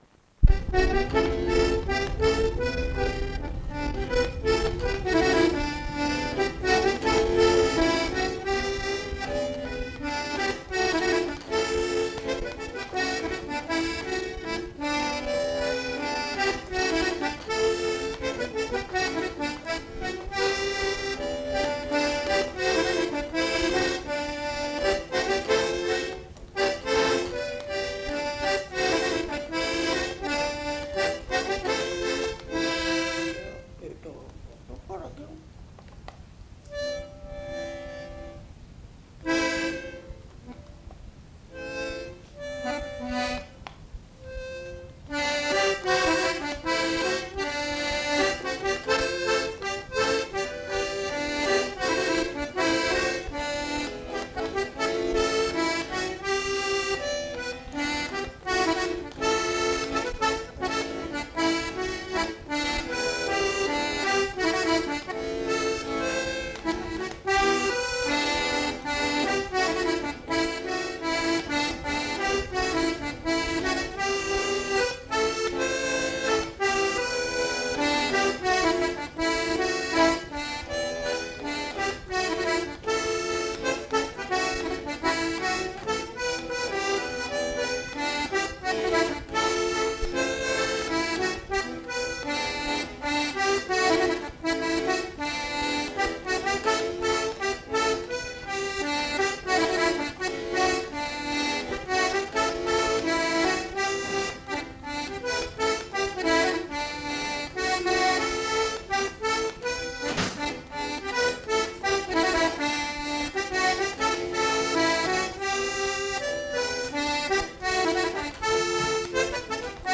Aire culturelle : Cabardès
Genre : morceau instrumental
Instrument de musique : accordéon diatonique
Danse : scottish